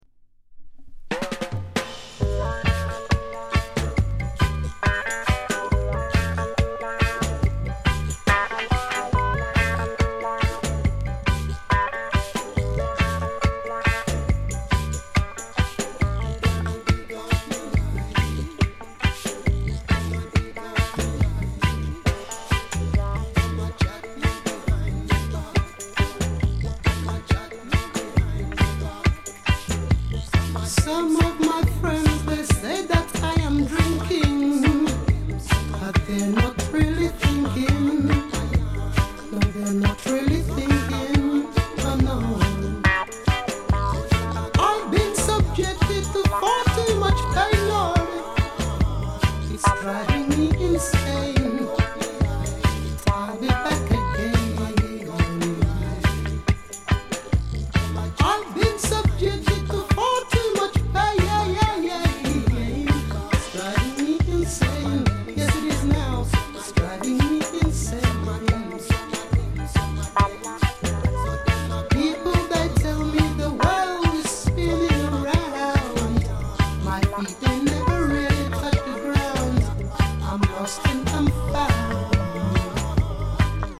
ソウルフル *